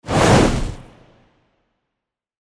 Fireball_2.mp3